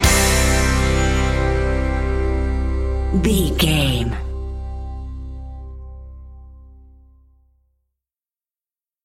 Ionian/Major
electric guitar
drums
bass guitar
Pop Country
country rock
bluegrass
happy
uplifting
driving
high energy